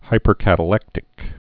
(hīpər-kătl-ĕktĭk)